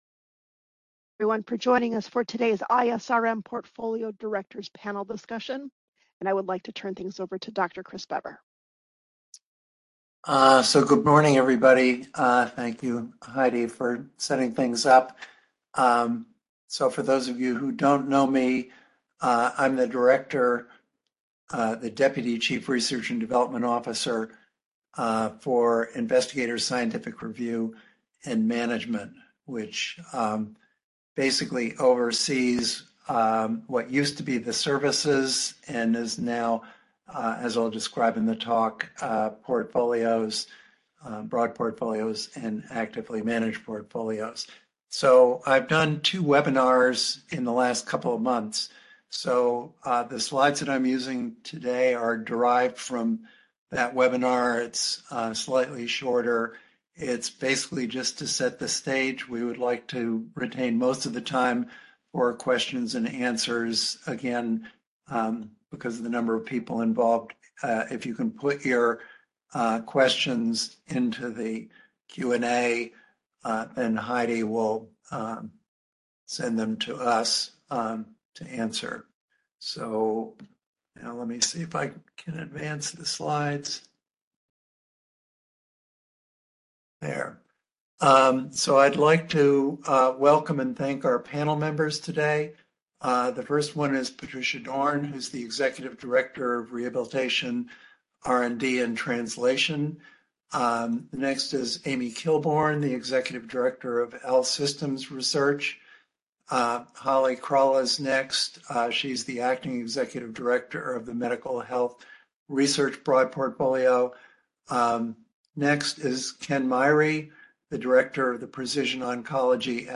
VA Intramural Research Award Program Panel Discussion